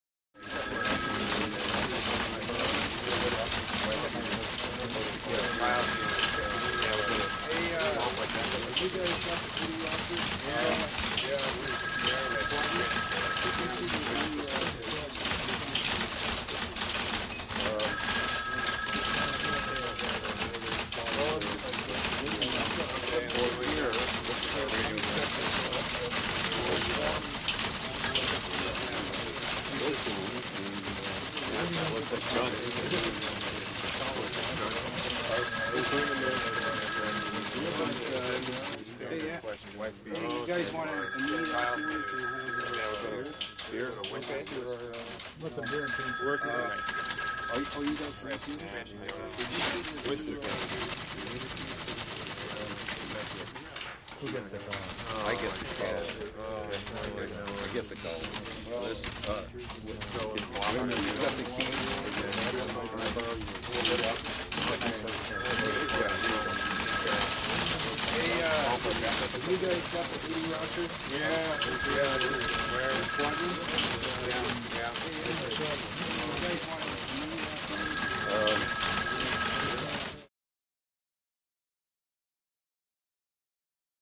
Police Station - Phone, Typewriters, Busy Walla